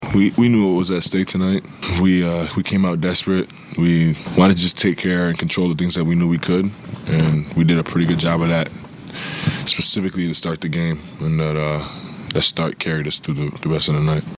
SGA postgame.